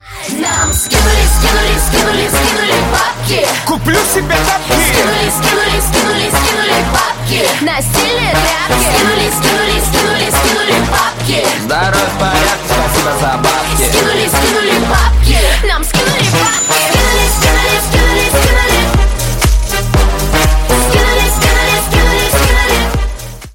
• Качество: 320, Stereo
мужской вокал
громкие
женский вокал
dance
Electronic